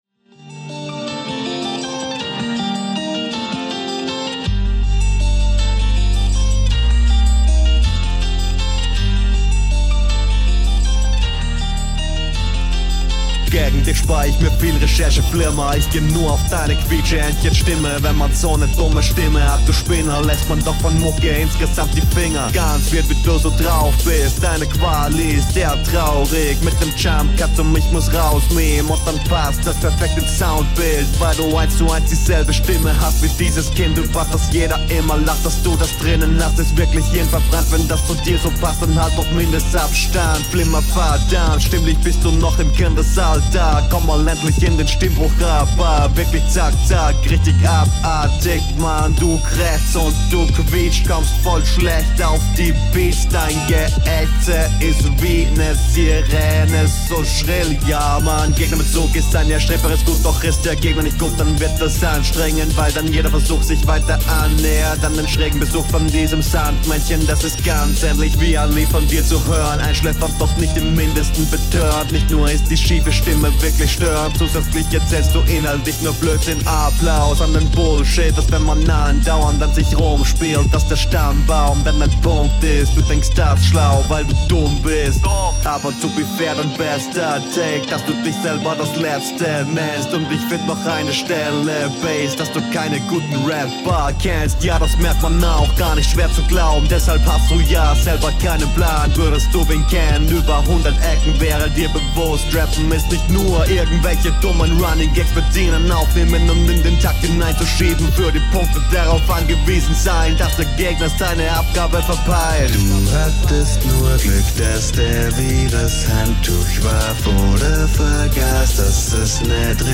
schlehcteste deiner runden, schade... soundquali auch komisch - liegt vermutlich am beat aber kp... klingt …